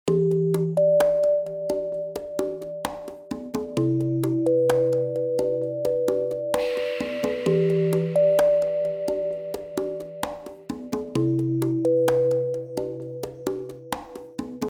красивые
спокойные
без слов